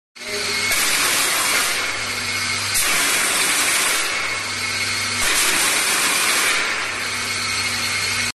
MK F02 X20 Fog Jet, comparison sound effects free download
comparison Mp3 Sound Effect MK-F02 X20 Fog Jet, comparison with co2 jet machine, similar effects, more convenient to use with, only need smoke liquid!